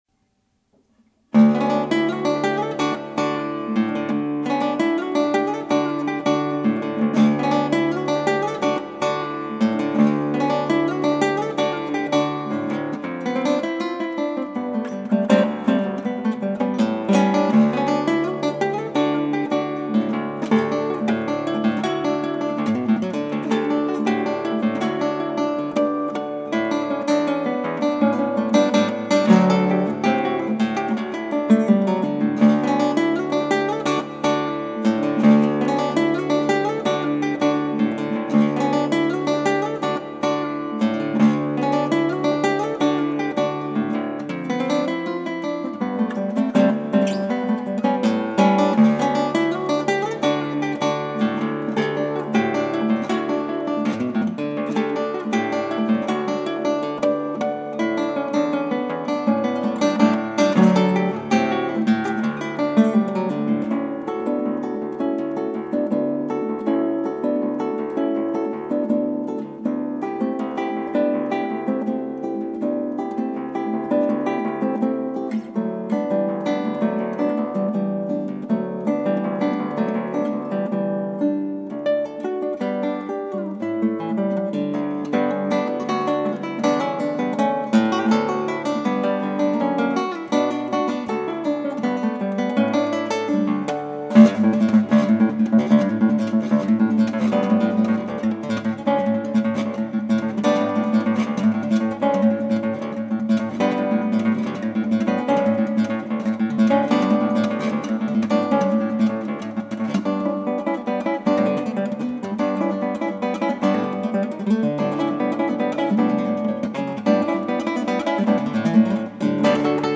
ギターの自演をストリーミングで提供
＿|‾|○ 途中で疲れてきて左手もつれていますね。